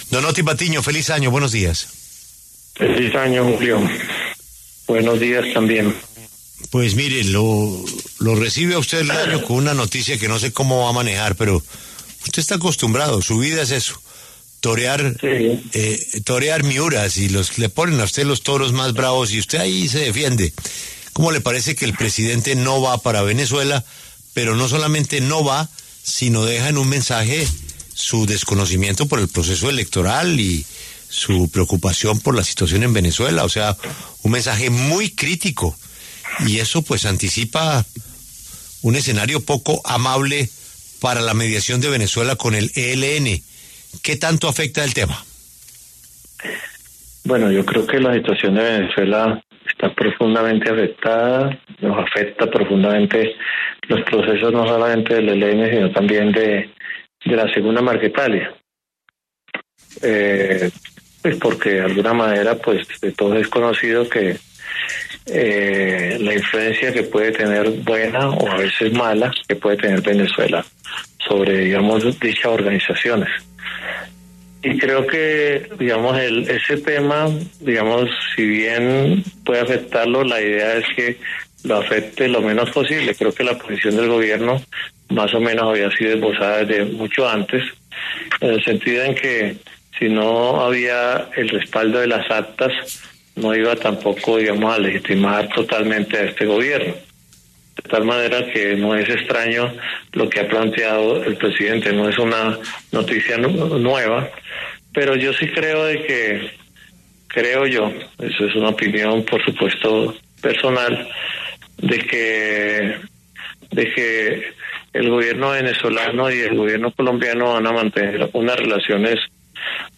El alto comisionado para la Paz, Otty Patiño, advirtió en una entrevista reciente sobre el posible impacto que pueden tener las declaraciones del presidente Gustavo Petro en contra de la situación política venezolana y su proceso electoral, en la mediación que ejerce el gobierno de Nicolás Maduro en el diálogo con el ELN.